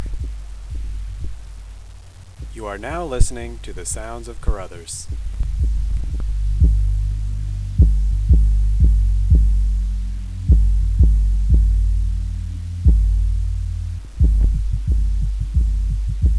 The typical noises of Caruthers Hall